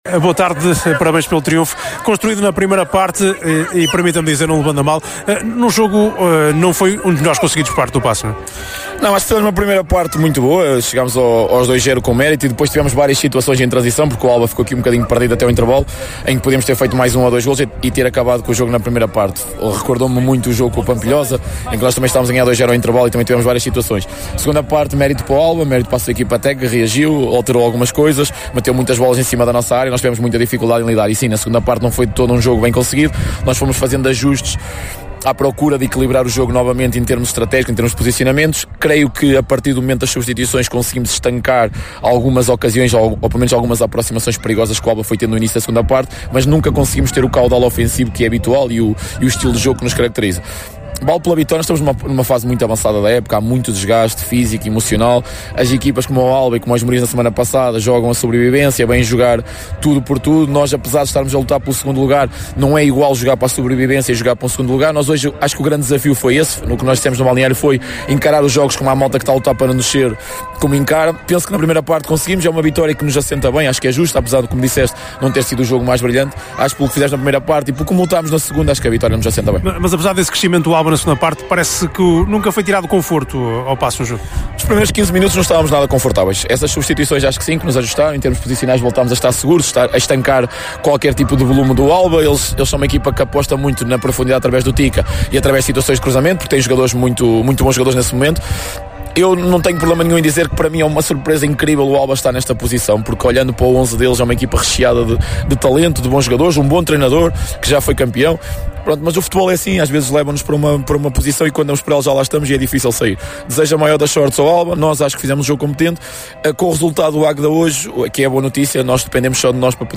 Aos microfones da Sintonia